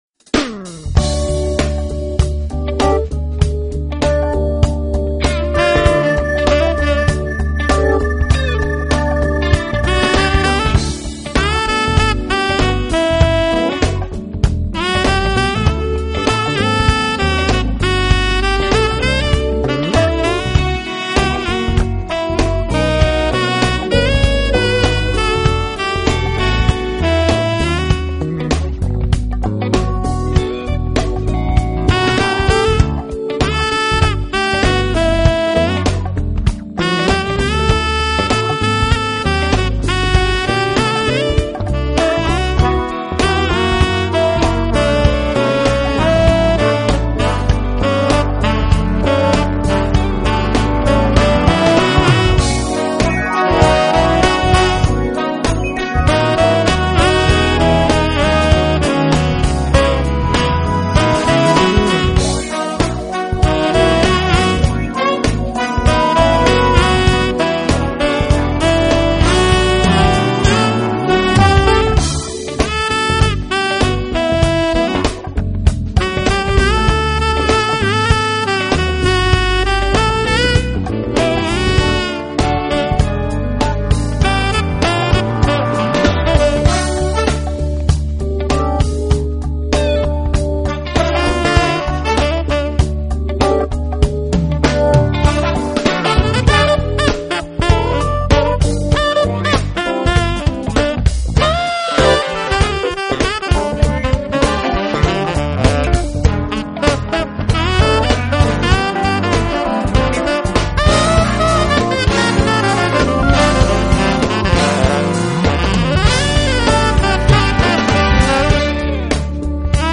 Genre : Jazz
Styles : Smooth Jazz,Saxophone